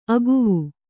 ♪ aguvu